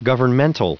Prononciation du mot governmental en anglais (fichier audio)
Prononciation du mot : governmental